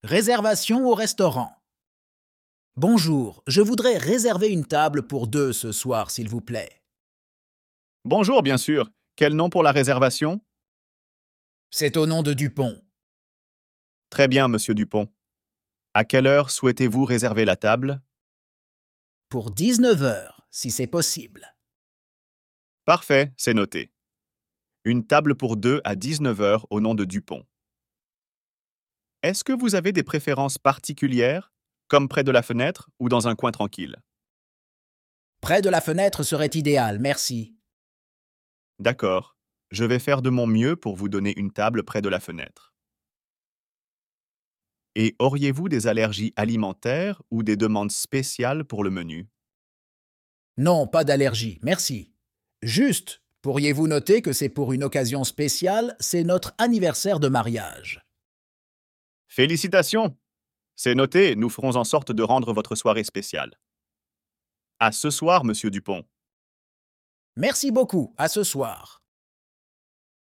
Dialogue FLE